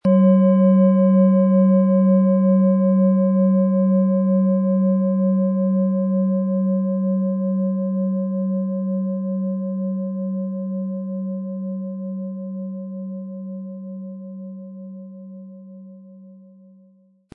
OM Ton
• Tiefster Ton: Biorhythmus Geist
Um den Original-Klang genau dieser Schale zu hören, lassen Sie bitte den hinterlegten Sound abspielen.
Durch die traditionsreiche Fertigung hat die Schale vielmehr diesen kraftvollen Ton und das tiefe, innere Berühren der traditionellen Handarbeit
Spielen Sie die OM-Ton mit dem beigelegten Klöppel sanft an, sie wird es Ihnen mit wohltuenden Klängen danken.
MaterialBronze